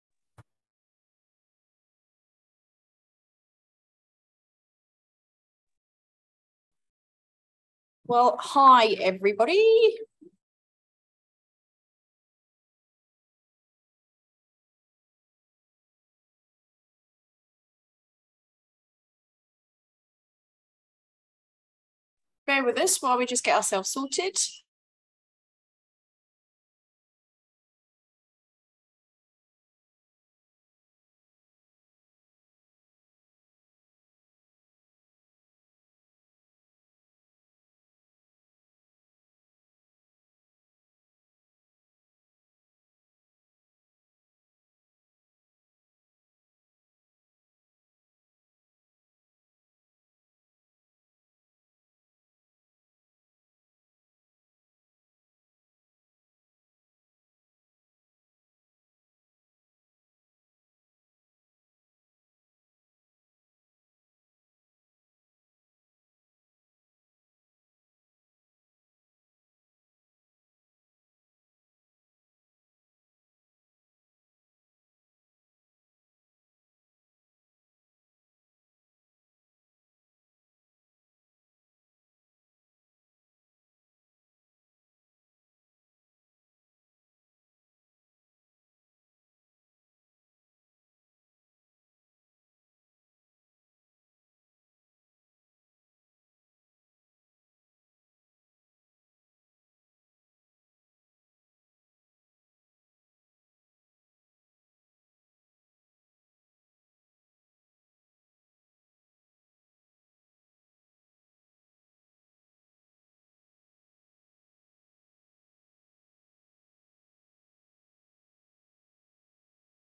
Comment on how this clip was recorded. We had some tech issues initially so best start the call at 12min30s